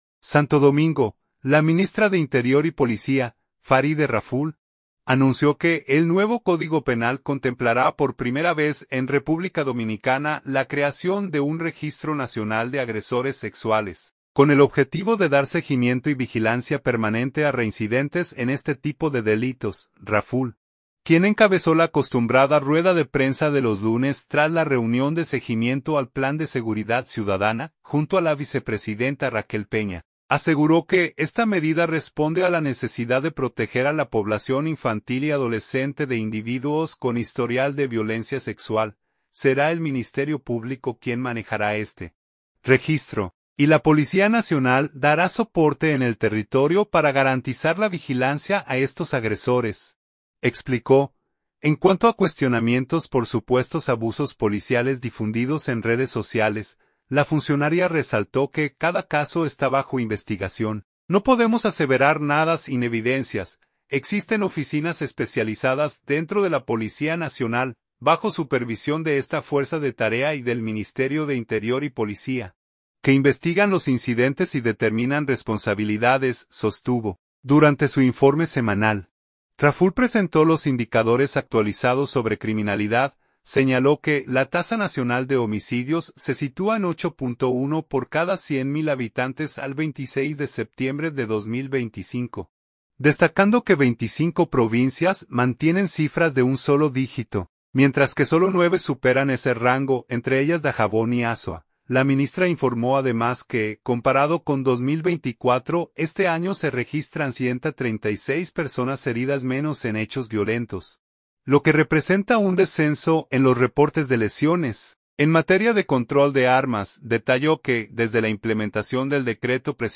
Raful, quien encabezó la acostumbrada rueda de prensa de los lunes tras la reunión de seguimiento al Plan de Seguridad Ciudadana, junto a la vicepresidenta Raquel Peña, aseguró que esta medida responde a la necesidad de proteger a la población infantil y adolescente de individuos con historial de violencia sexual.